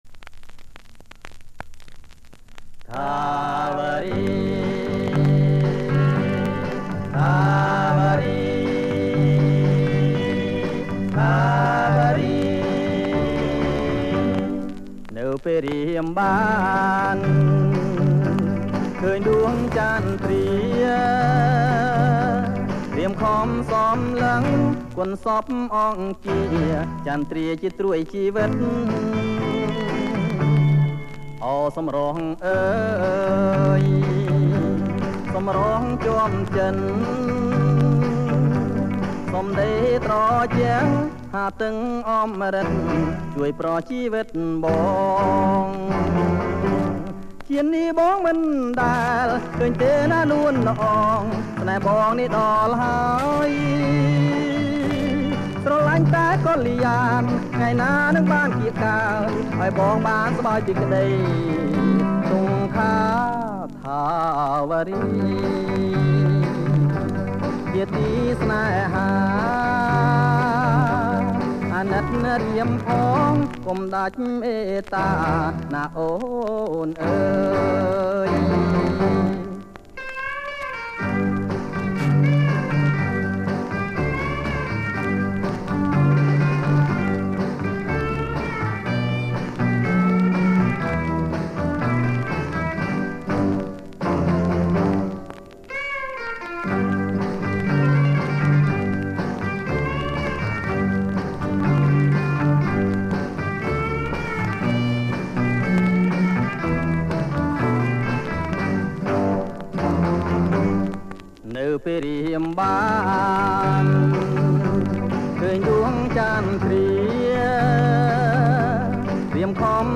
• ប្រគំជាចង្វាក់ Surf